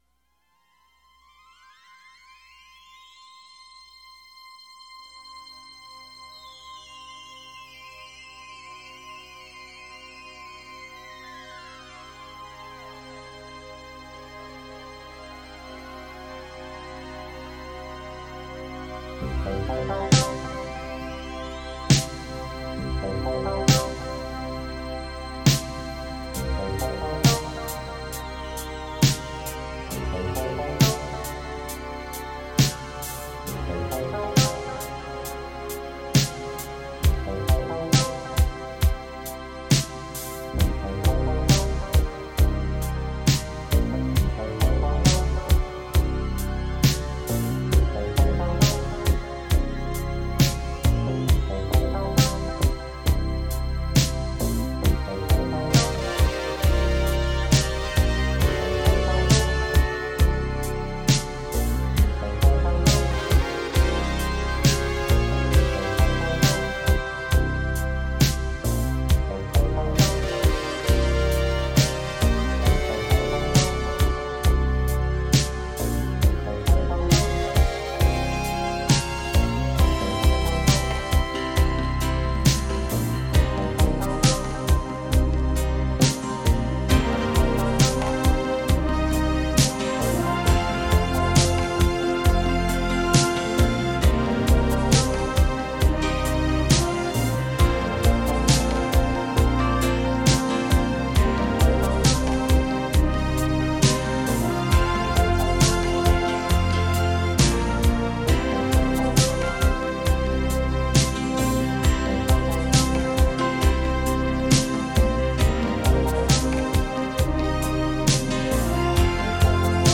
粗犷的旋律、魔幻般的电子配器、异常过瘾的音响效果、劲抽到近乎HARD ROCK 的节奏和冲击力，令聆听者不能抵抗。